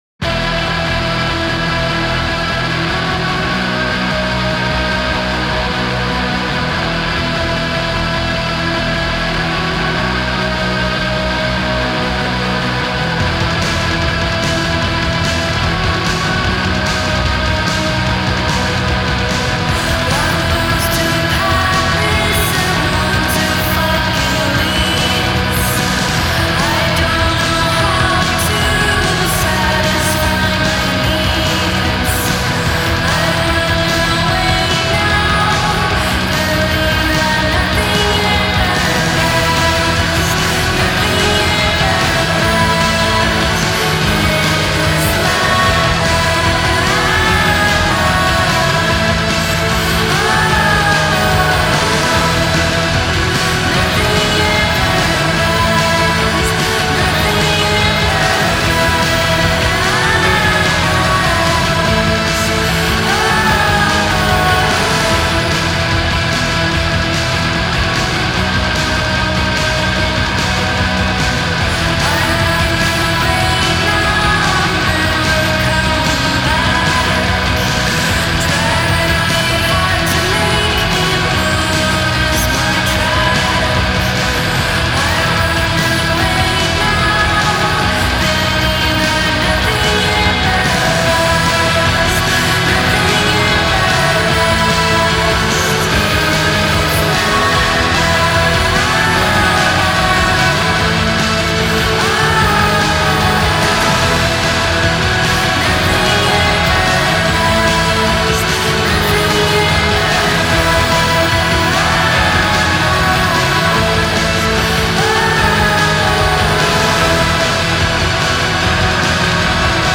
Muddy, soaring Swedish shoegaze